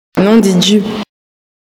uitspraak
nom_de_dju_prononciation.mp3